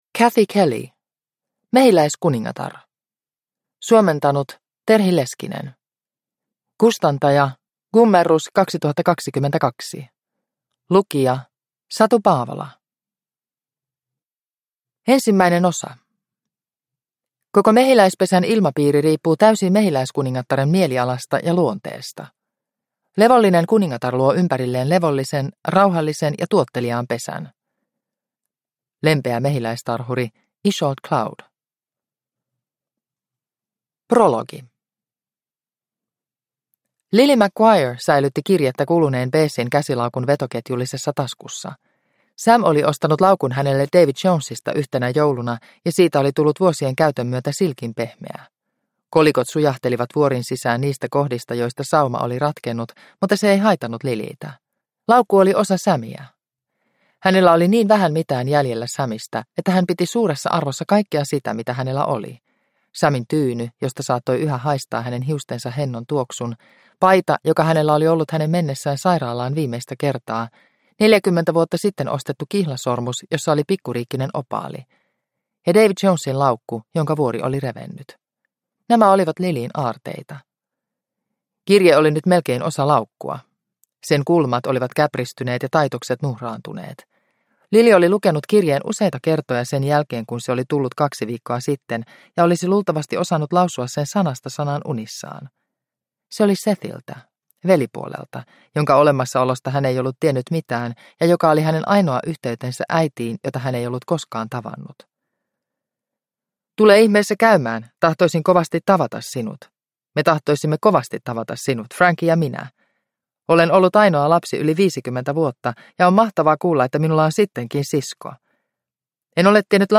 Mehiläiskuningatar – Ljudbok – Laddas ner